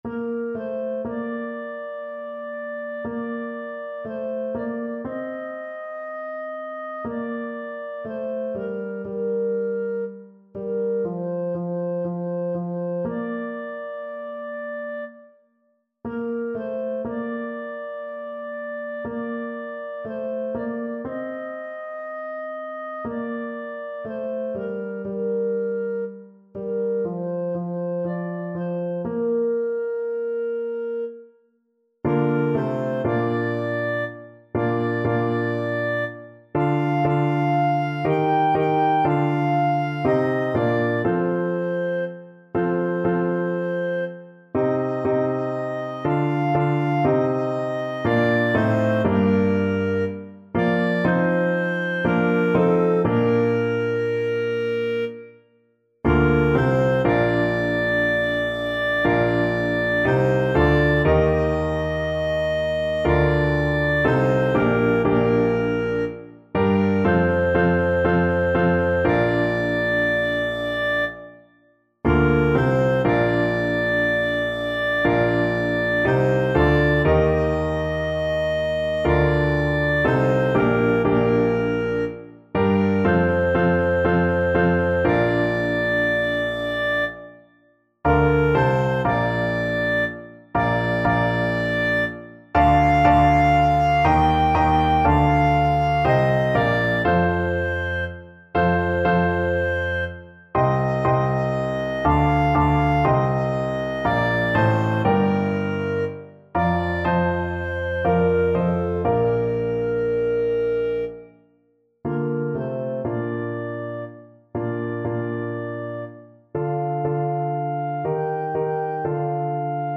Clarinet
Bb major (Sounding Pitch) C major (Clarinet in Bb) (View more Bb major Music for Clarinet )
4/4 (View more 4/4 Music)
Slow and expressive =c.60
Traditional (View more Traditional Clarinet Music)